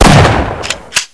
shotgunShootPump.ogg